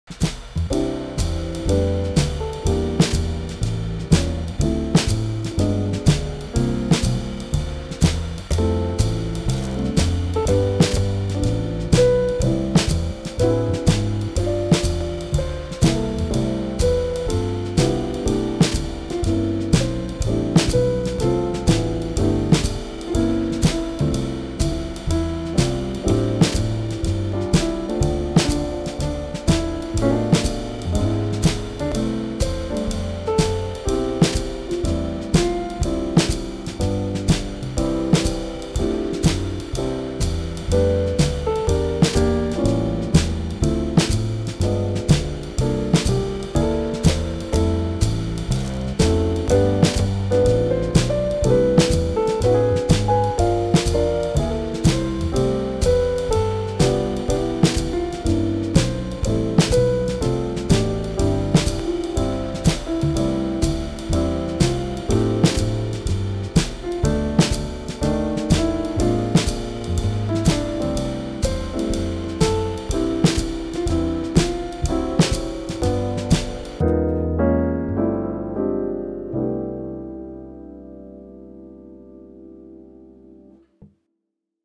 Christmas, Holiday, Jazz
C Major